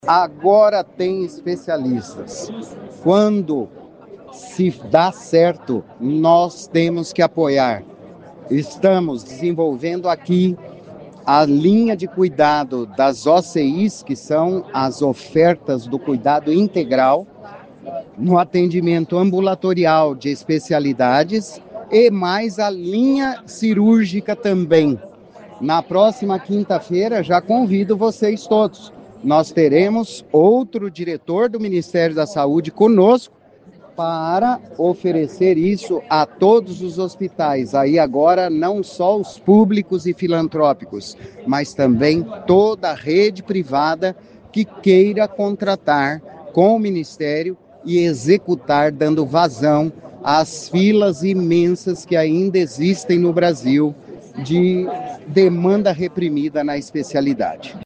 O secretário de Saúde de Maringá Antônio Carlos Nardi falou sobre o assunto nesse domingo (13) durante a assinaturas de portarias no Hospital da Criança.